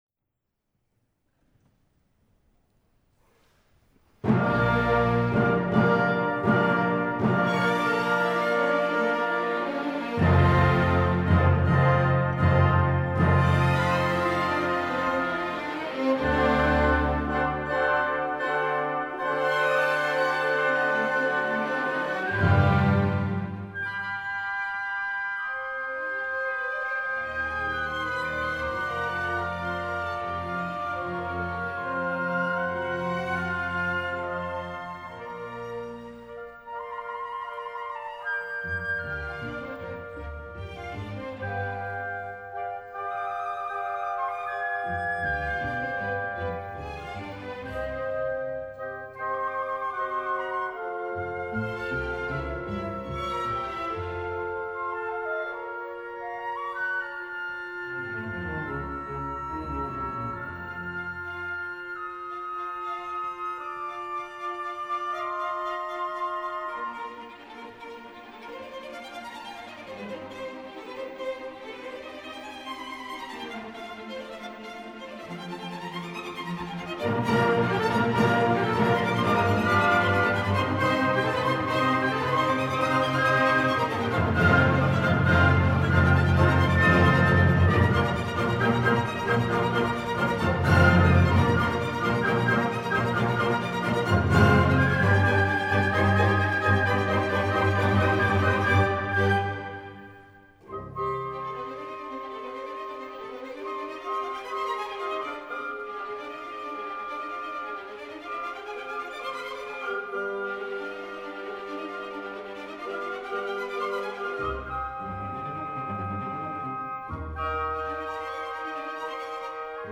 Language English Fecha: Wednesday, 30 September, 2020 Duración: 27:08 Audio: 02schubert300920.mp3 Temporada: Temporada 2020/2021 Audio promocionado: Autor: Franz Schubert Categoría: Concert season ¿Pertenece a algún disco?: